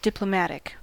Ääntäminen
IPA : /ˌdɪp.ləˈmæ.tɪk/